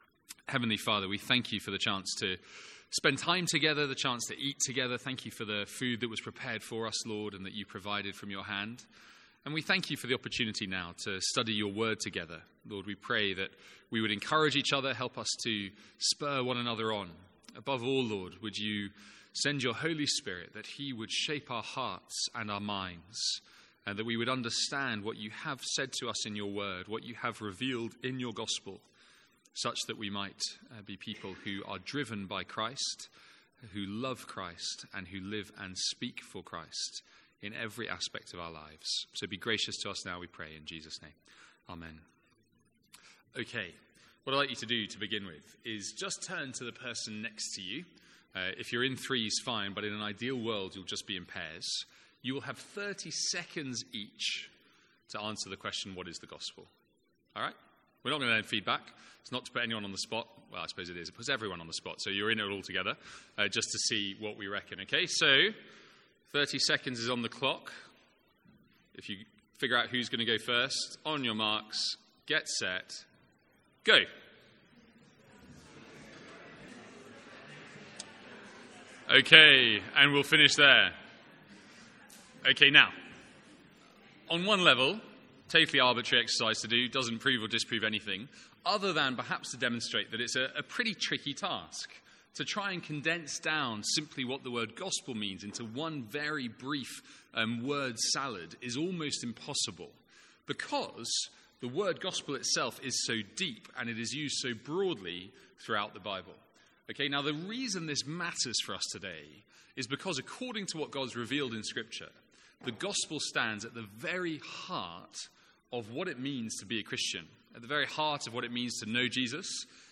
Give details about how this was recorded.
The first of our student lunches for this academic year.